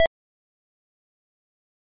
描述：little blip
标签： pong blip beep